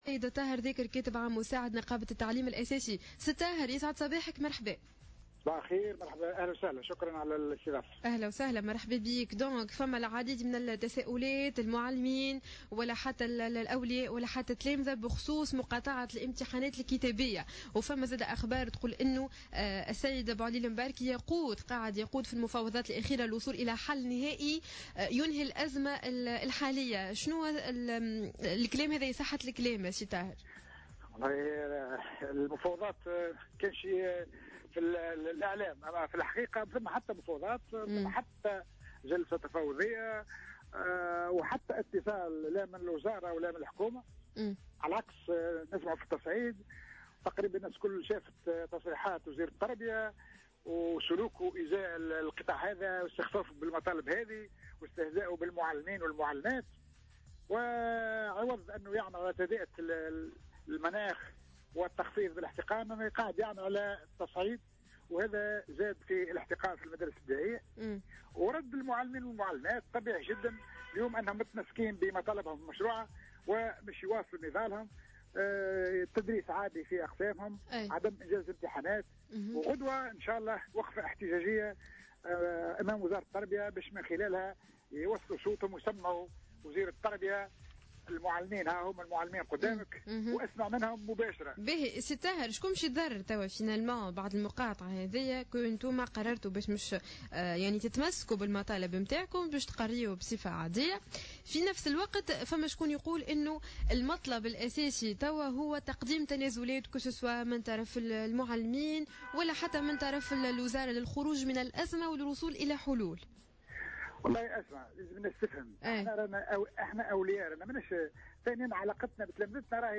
في مداخلة له على جوهرة اف ام